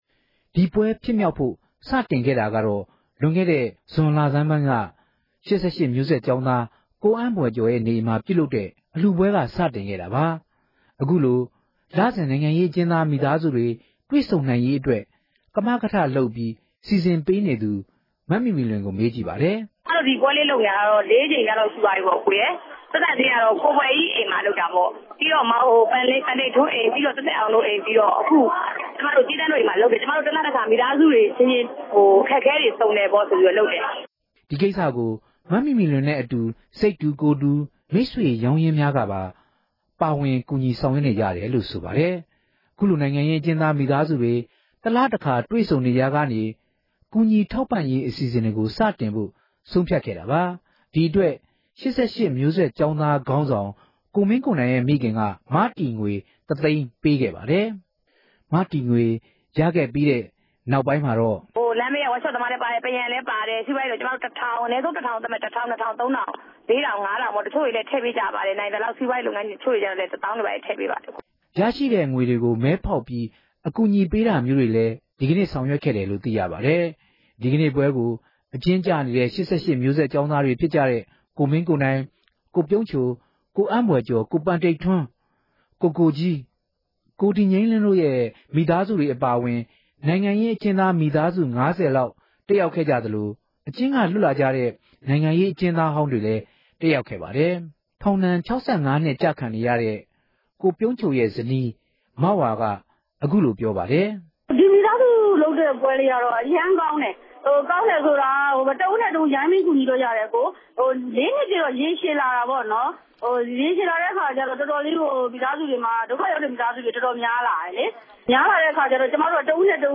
မေးမြန်းတင်ပြချက်။